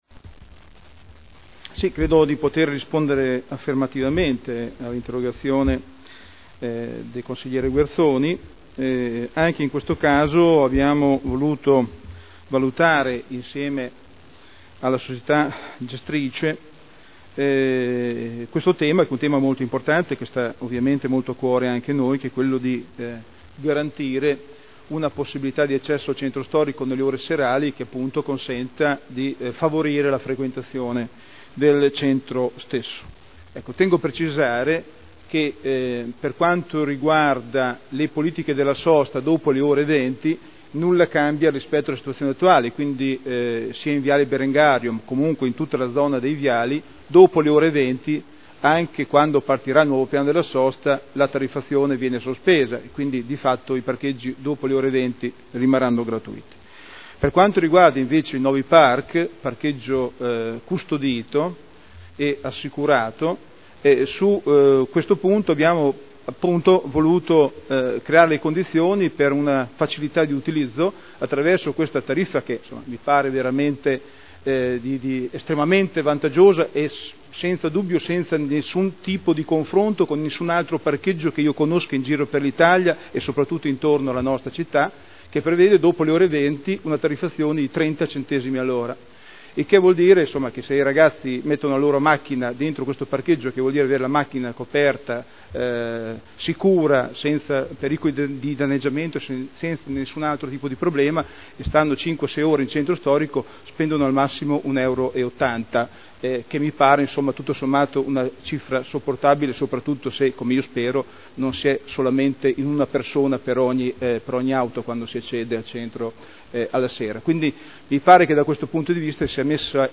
Seduta del 30/05/2011. Risponde a interrogazione del consigliere Guerzoni (P.D.) avente per oggetto: “Parcheggio Novi Park e “Movida” in centro storico”